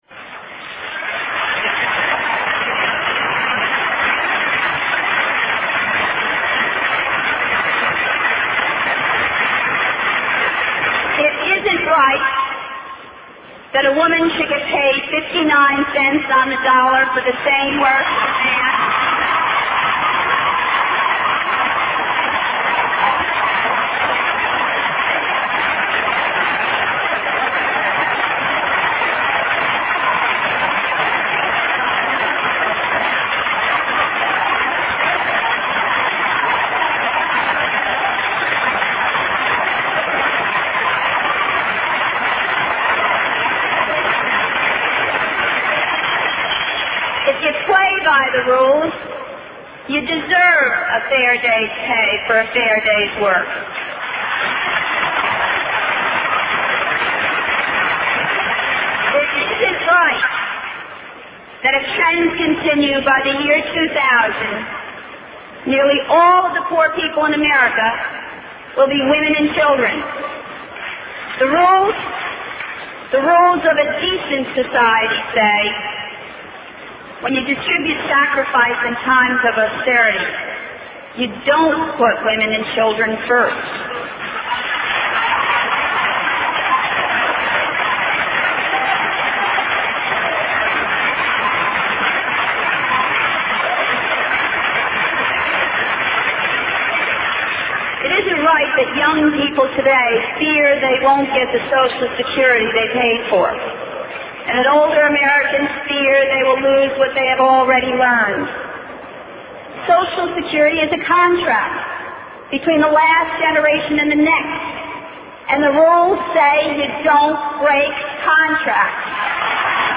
经典名人英语演讲(中英对照):Vice-Presidential Nomination Acceptance Speech 4